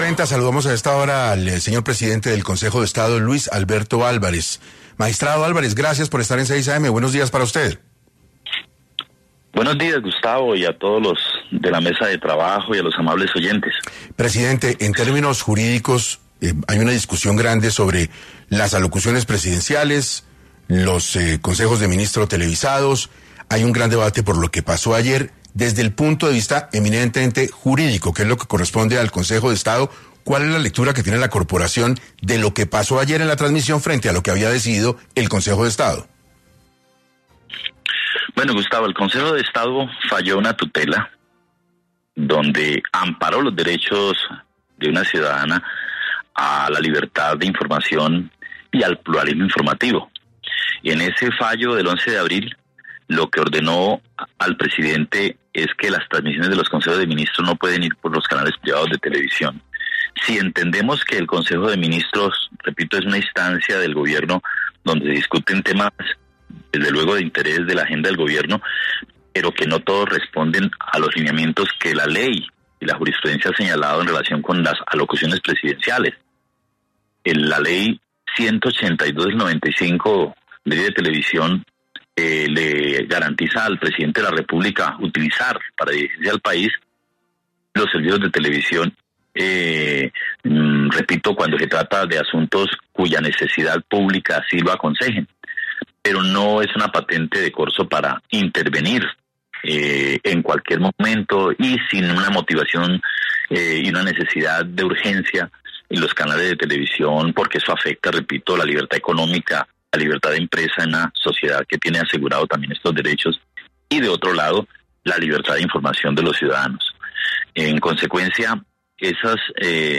Para 6AM habló el Magistrado Luis Alberto Álvarez, presidente del Consejo de Estado, sobre las alocuciones y consejos de ministros ¿Está Gustavo Petro desacatando la constitución?